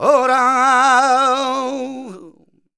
Index of /90_sSampleCDs/Best Service ProSamples vol.46 - Flamenco [AKAI] 1CD/Partition D/MALECANTAOR1